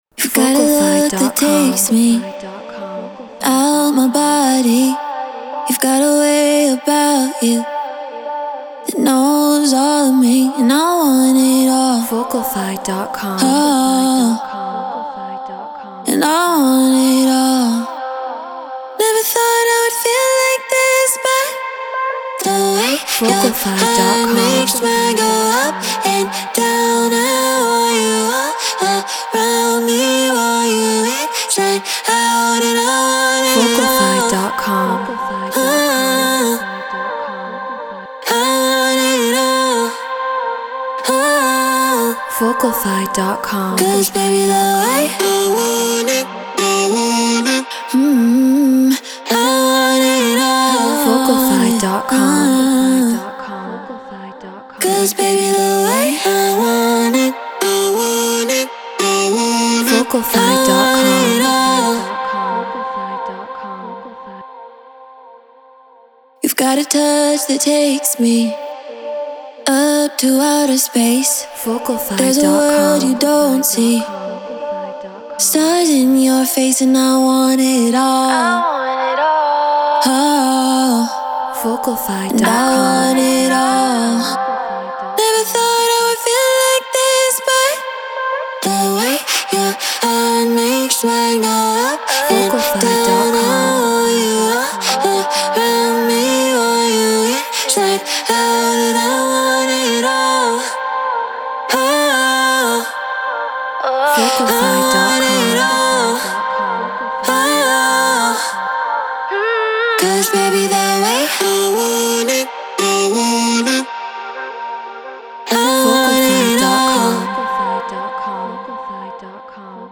Drum & Bass 174 BPM Gmin